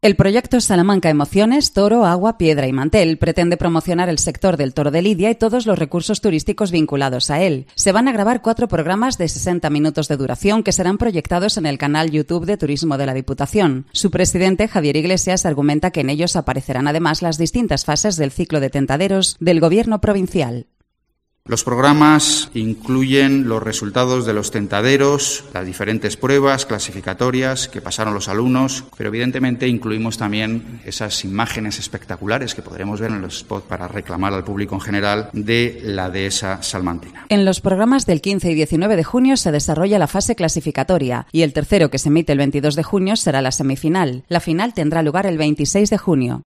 El presidente de la Diputación de Salamanca, Javier Iglesias, presenta el proyecto turístico-taurino